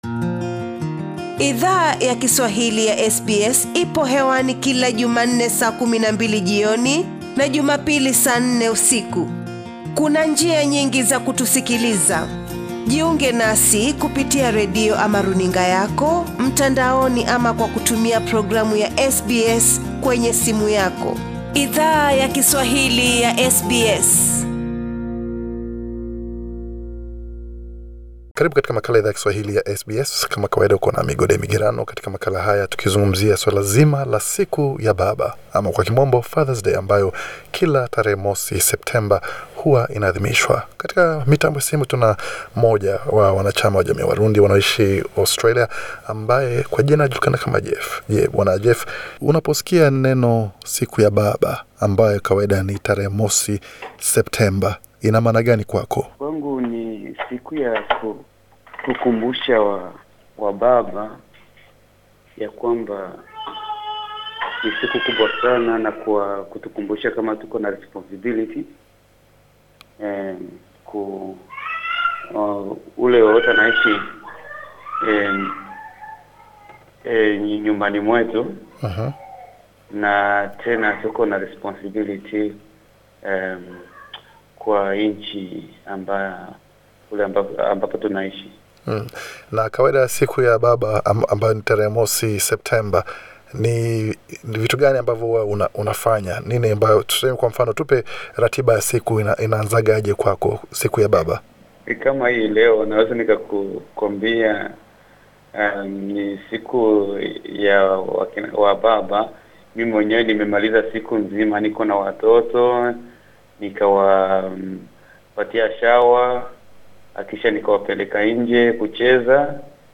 Baadhi ya wanaume kutoka jamii zawa hamiaji, walieleza Idhaa ya Kiswahili ya SBS, umuhimu wakuadhimisha siku kuu ya kina baba. Bofya hapo juu, kusikiza mahojiano kamili.